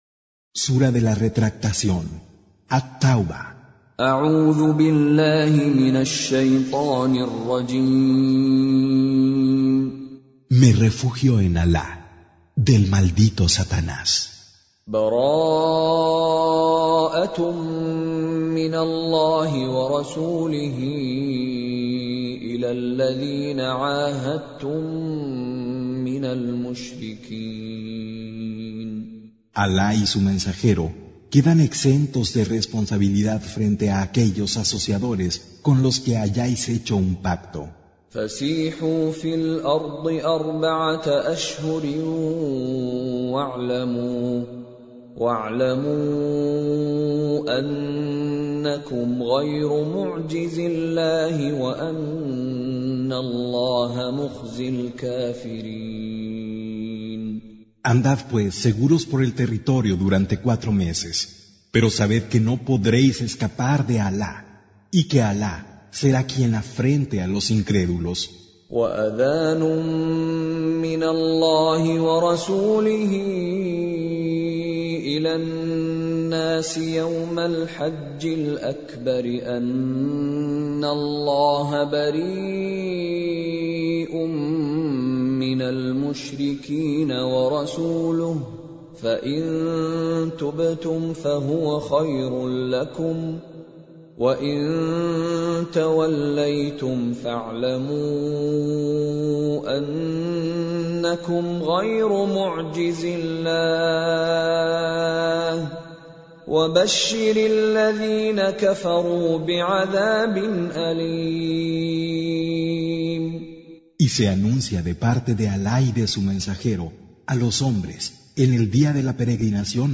Traducción al español del Sagrado Corán - Con Reciter Mishary Alafasi
Surah Repeating تكرار السورة Download Surah حمّل السورة Reciting Mutarjamah Translation Audio for 9. Surah At-Taubah سورة التوبة N.B *Surah Excludes Al-Basmalah Reciters Sequents تتابع التلاوات Reciters Repeats تكرار التلاوات